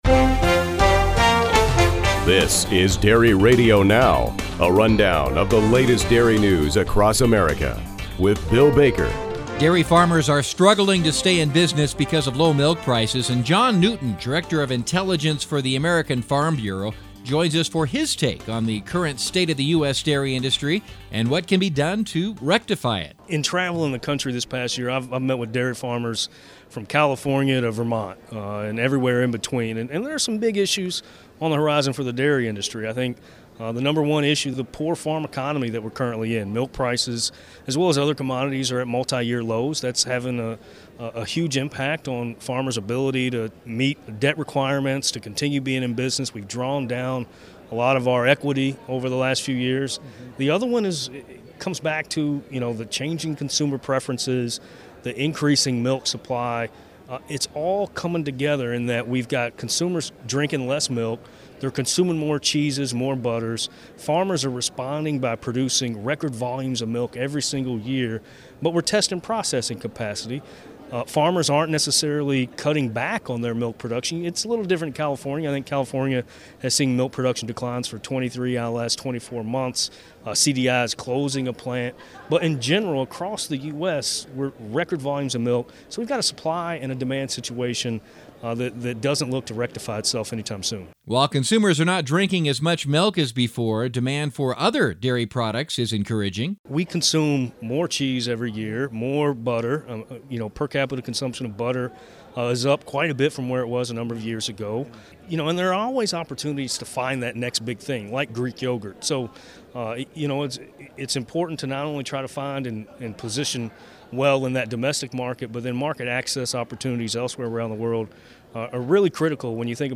He tells Dairy Radio Now that dairy producers are dealing with more supply than demand.